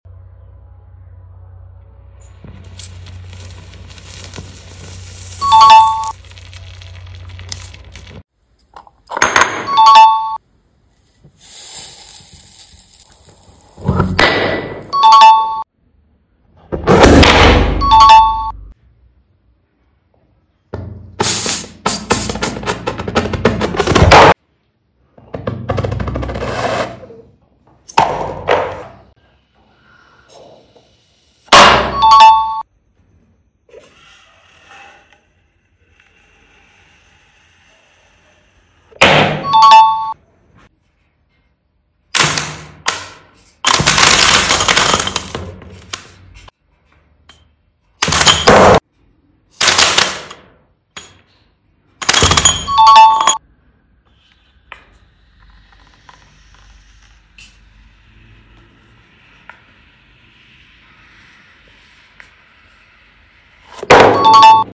1000°C Red Hot Metal Ball sound effects free download
1000°C Red Hot Metal Ball VS Aluminum+White Tape+Spunch+Tape+Plastic Ball+DVD+Rubber Band and Disposable Glass